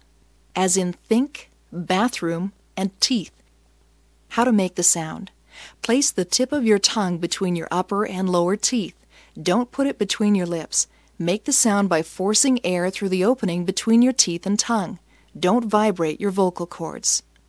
後で紹介するフリーソフトでWaveファイルに変換し、さらに別のフリーソフトで減速させました。
あくまでもフリーソフトなので音質はあまりよくありませんが、聞き取りには十分でしょう。